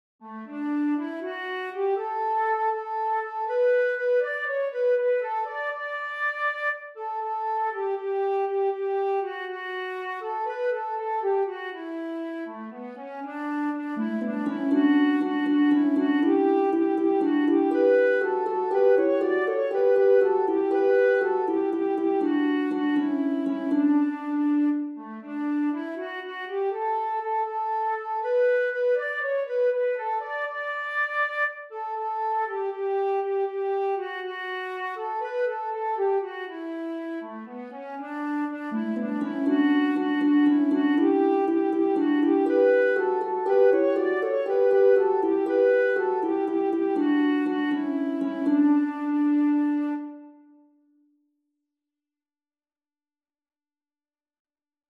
Vrolijk vertellend